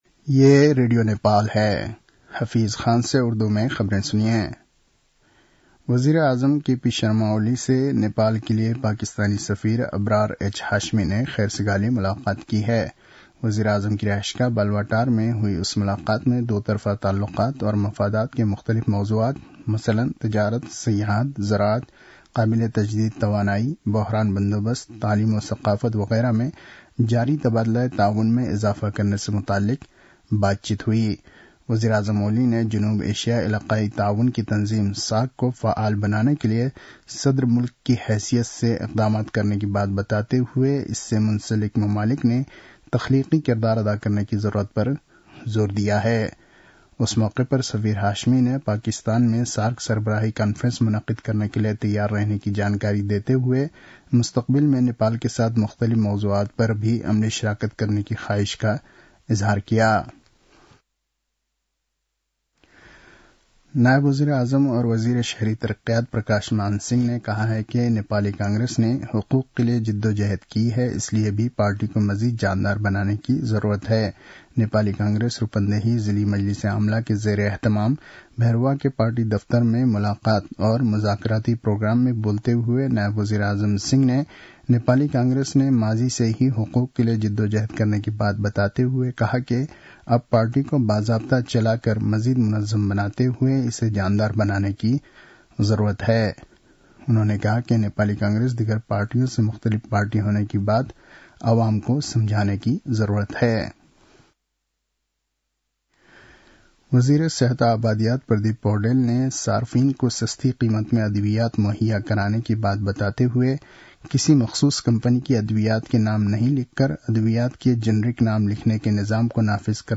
उर्दु भाषामा समाचार : १० पुष , २०८१